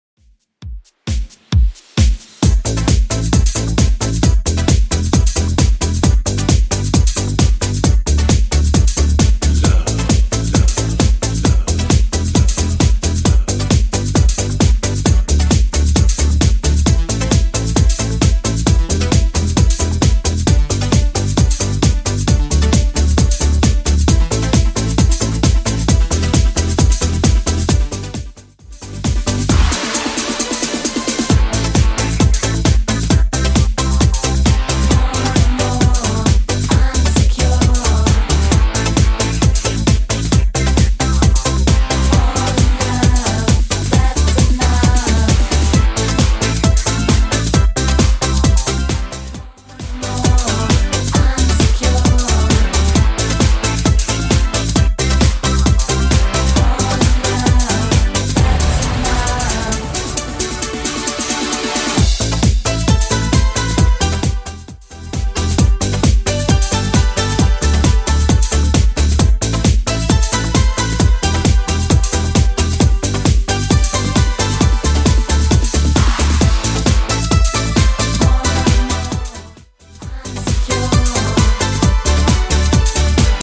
Genre: 80's
BPM: 105